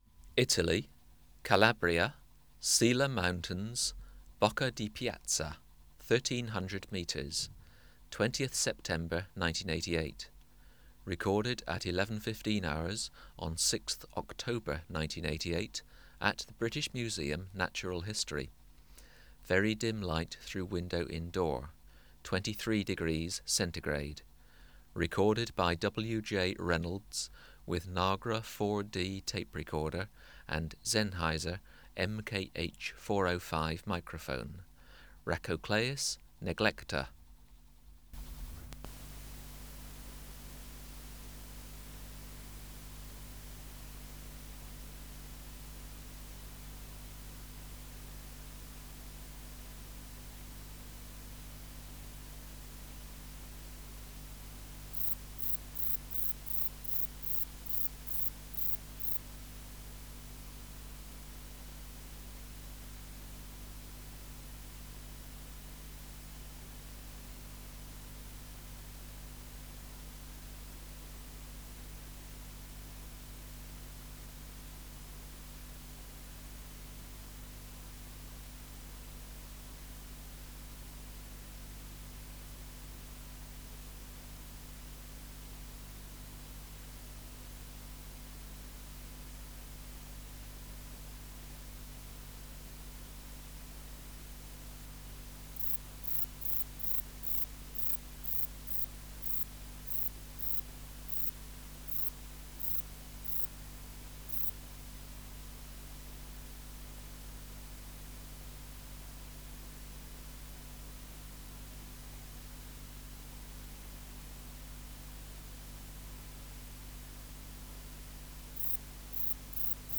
572:12 Conocephalus conocephalus (709a) | BioAcoustica
Natural History Museum Sound Archive Species: Conocephalus (Conocephalus) conocephalus